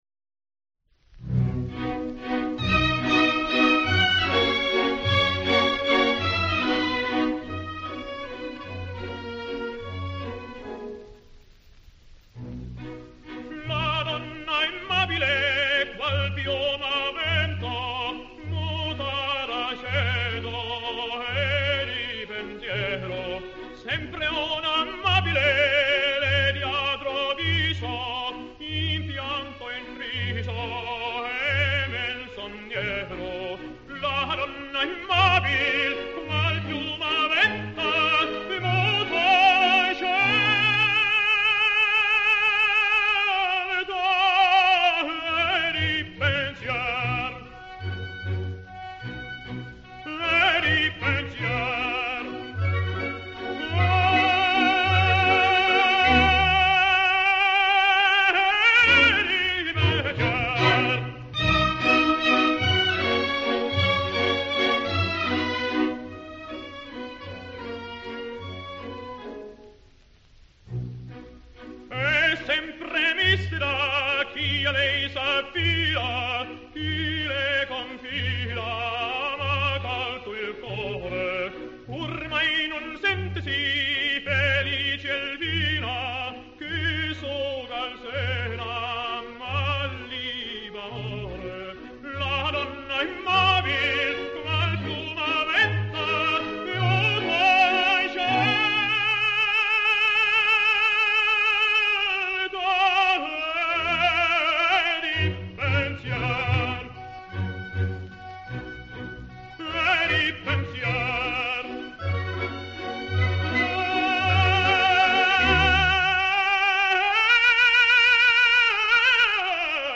Varje ton han sjunger är utan vank och brist.